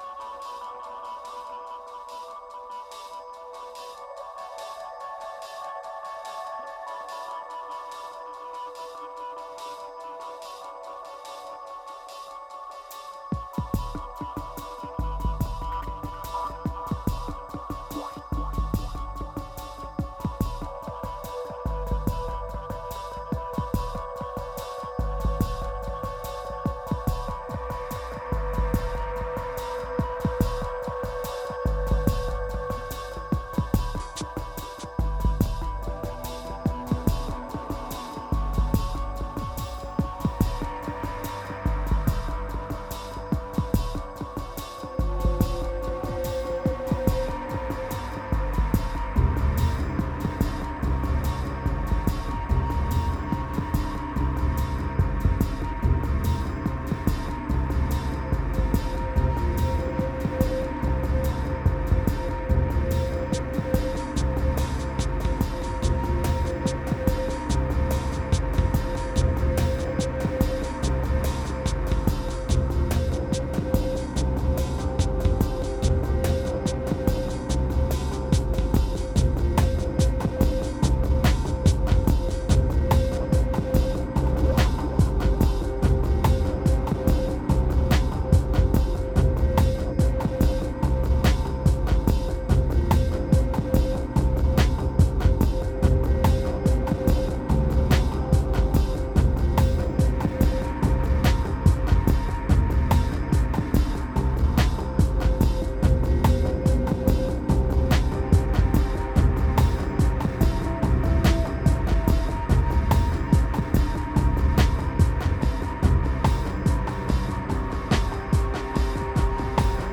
2333📈 - 23%🤔 - 72BPM🔊 - 2010-12-18📅 - -66🌟